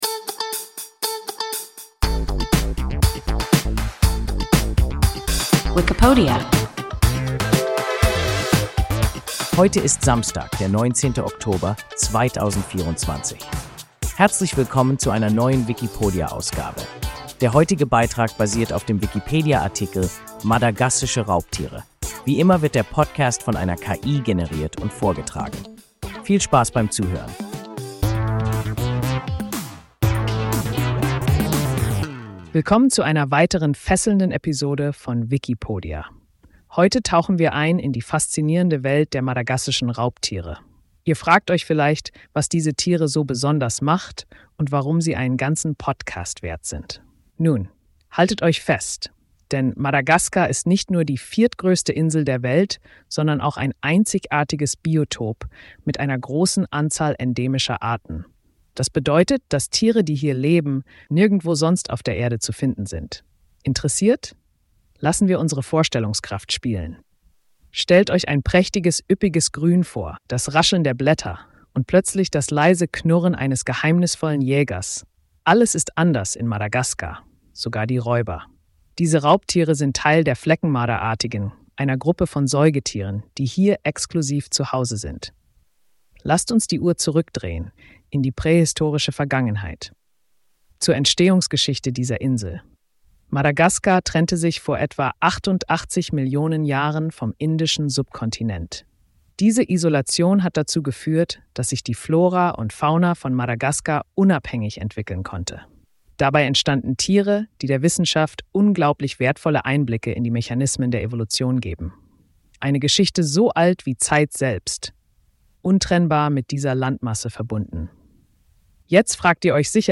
Madagassische Raubtiere – WIKIPODIA – ein KI Podcast